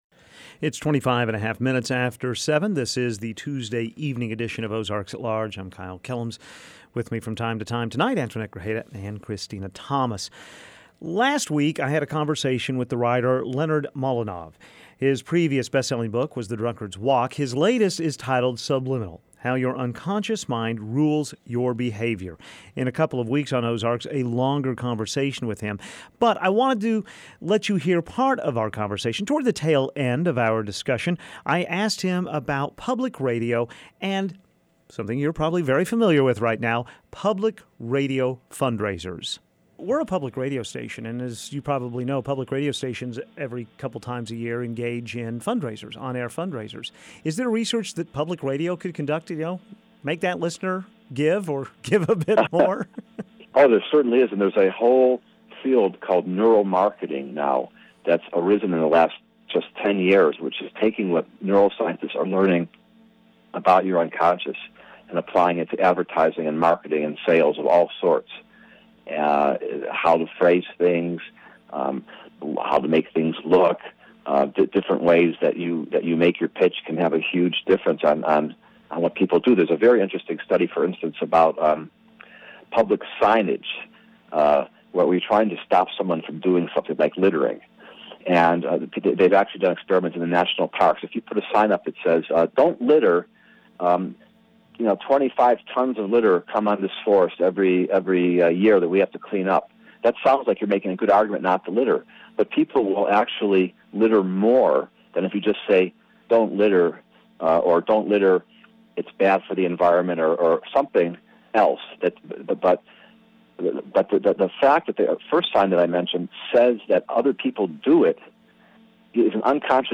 A conversation with writer \Leonard Mlodinow about his book Subliminal and how best to convince listeners to support public radio.